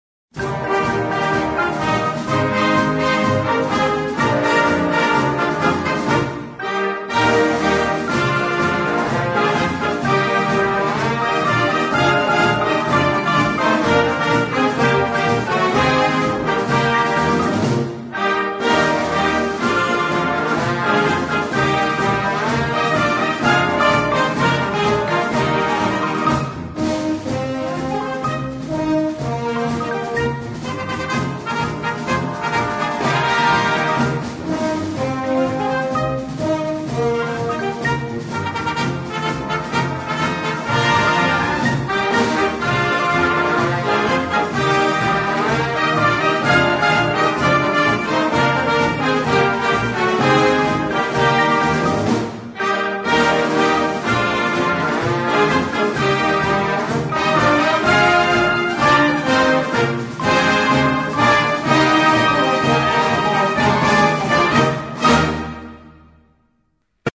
Besetzung: Blasorchester
ist ein unterhaltendes Konzertstück.